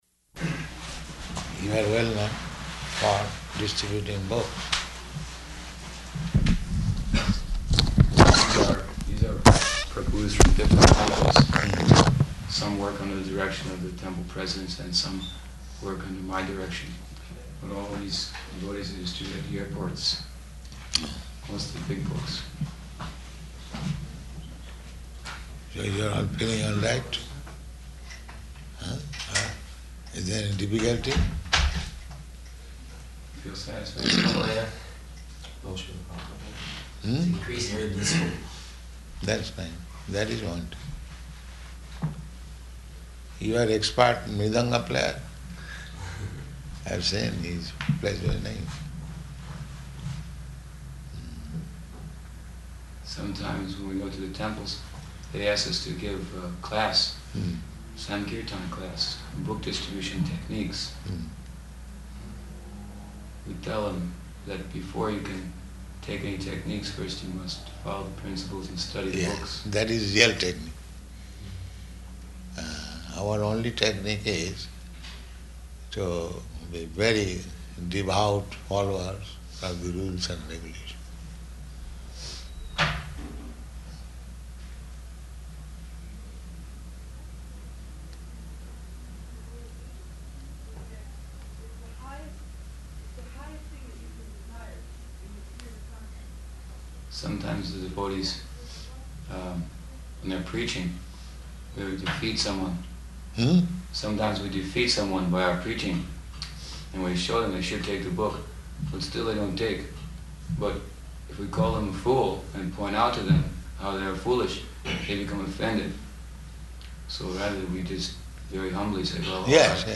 -- Type: Conversation Dated: March 2nd 1975 Location: Atlanta Audio file